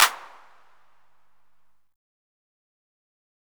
kits/Southside/Claps/BWZYCl_2.wav at main